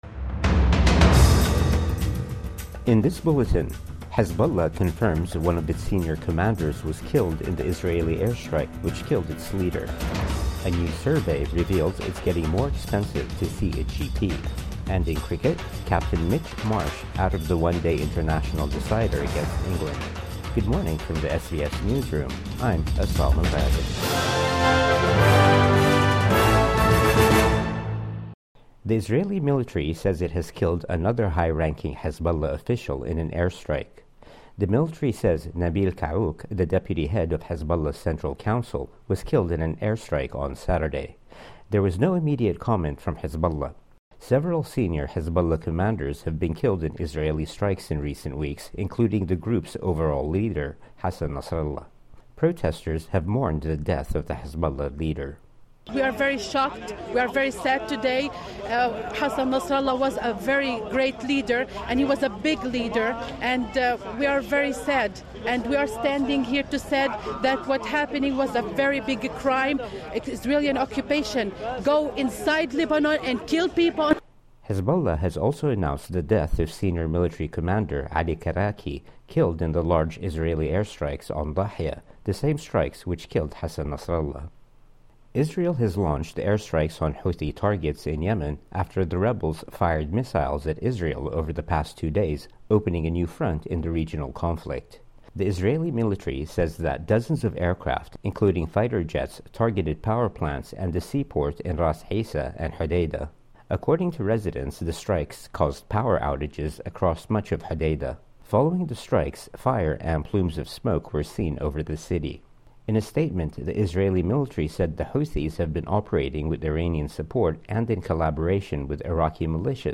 Morning News Bulletin 30 September 2024